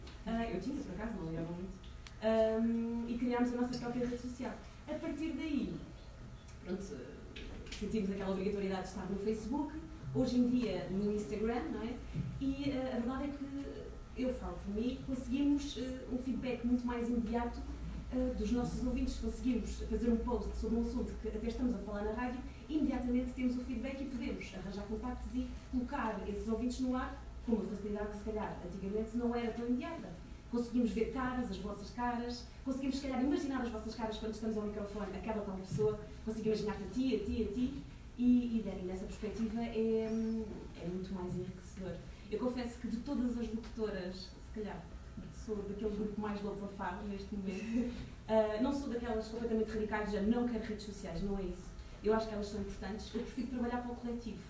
描述：从旧收音机录下的嘈杂的收音机声音
Tag: 静电 噪声 无线电